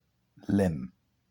Lympne (/lɪm/